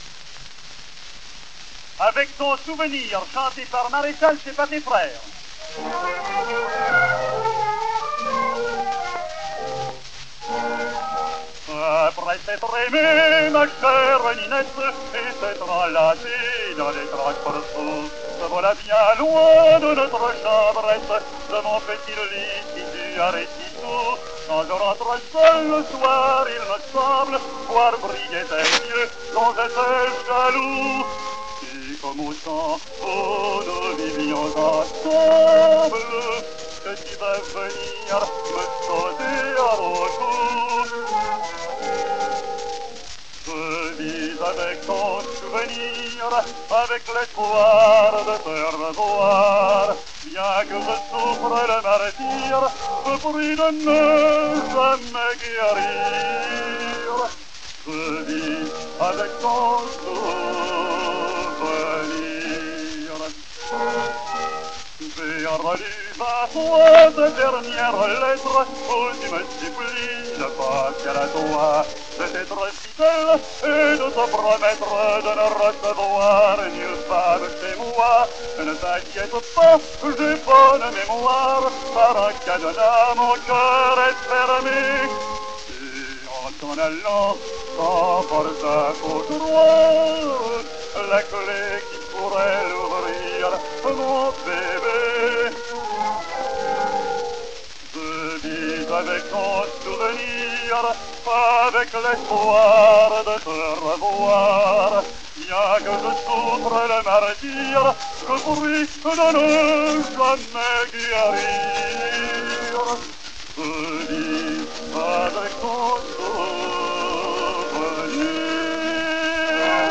mit Orchesterbegleitung